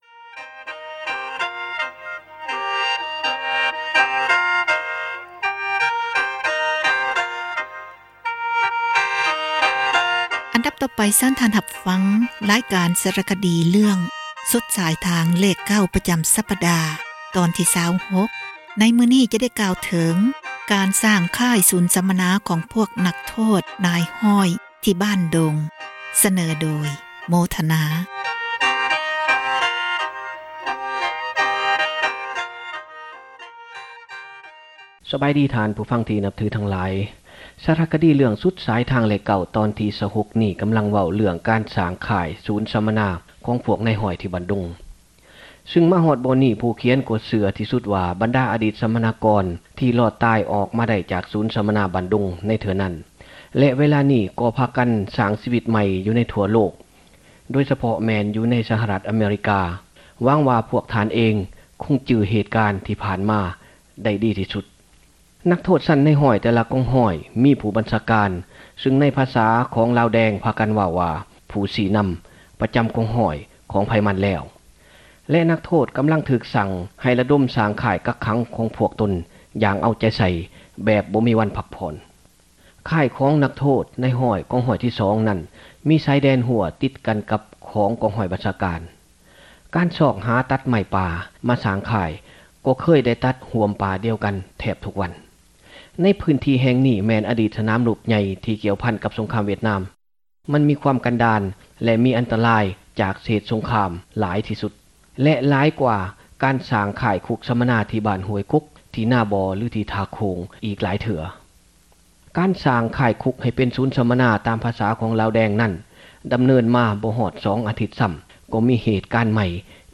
ສາຣະຄະດີ ເຣື້ອງ ສຸດສາຍທາງເລຂ 9 ຕອນທີ 26 ຈະໄດ້ເລົ່າເຖິງ ການສ້າງຄ້າຍ ສູນ ສັມມະນາ ຂອງພວກ ນັກໂທດ ນາຍຮ້ອຍ ທີ່ ບ້ານດົງ.